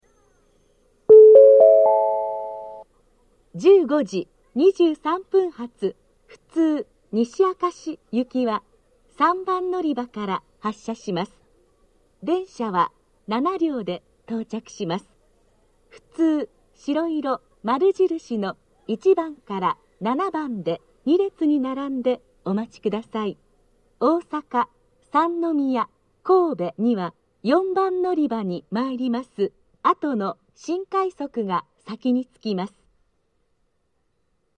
（女性）
到着予告放送 15：23発 普通 西明石行き 7両編成の自動放送です。